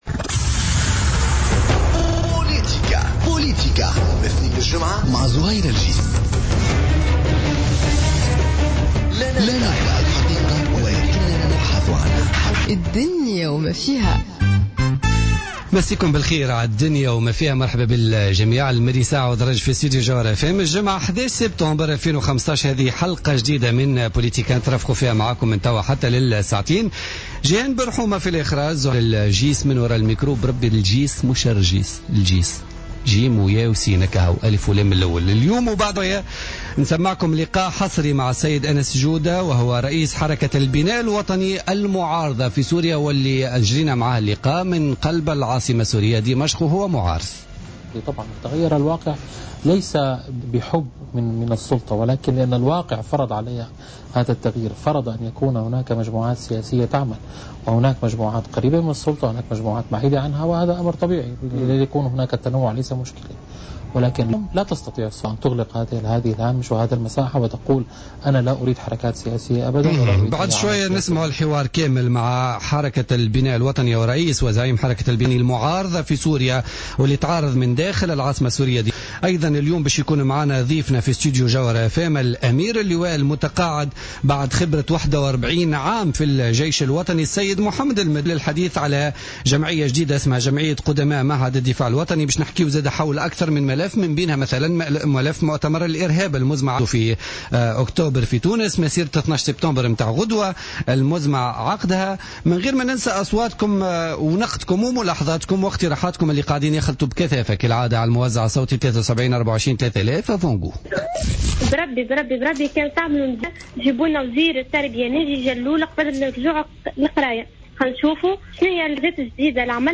Interview avec le Général de brigade à la retraite de l'armée nationale